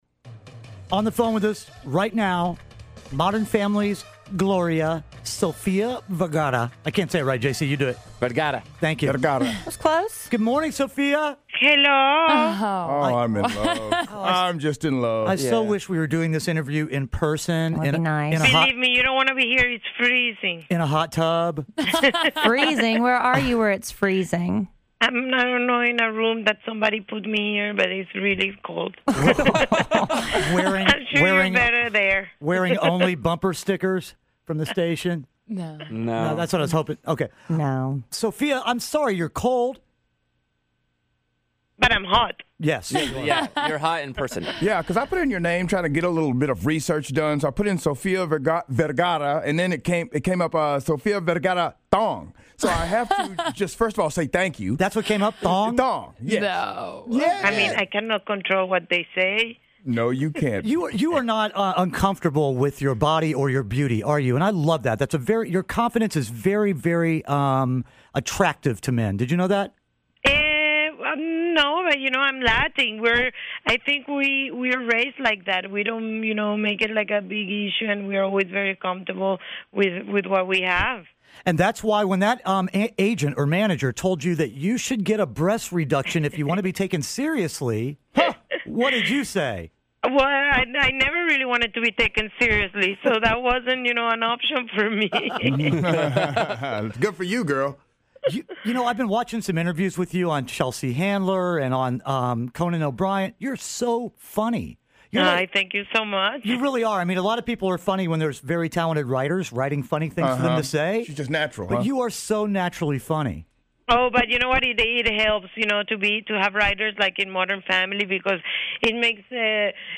Sofia Vergara Interview
Kidd Kraddick in the Morning interviews Sophia Vergara!